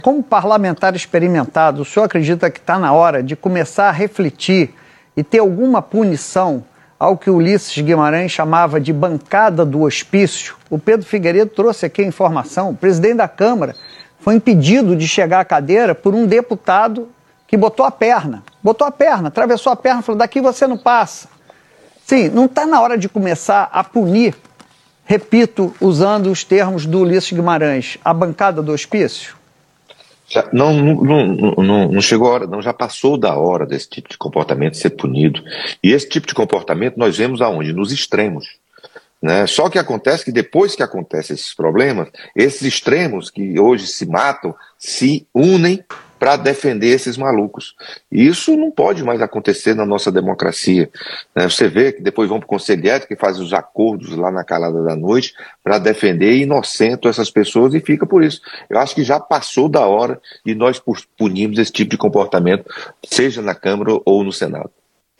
Confira o aúdio com trecho da entrevista concedida por Ciro à Globo News:
sonora-ciro-nogueira-bancada-hospicio-bsb-reproducao-globo-news.mp3